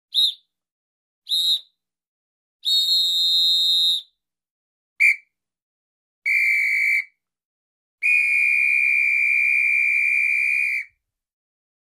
Звуки свистка
Звук свистка полицейского — вариант 2